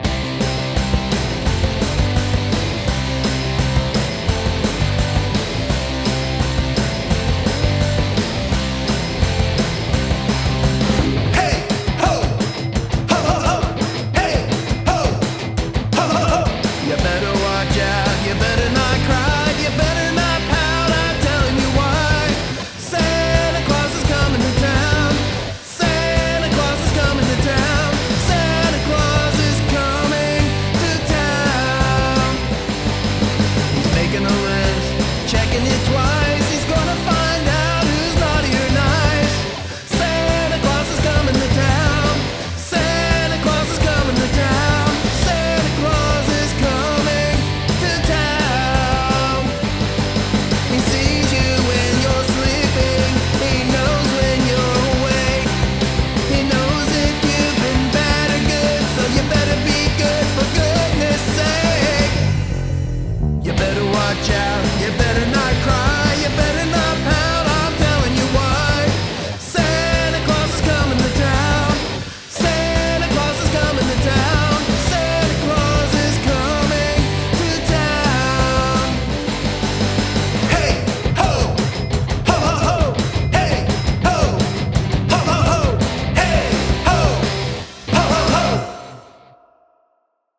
High energy rock'n'roll
That bass is excellent as is the rest of the track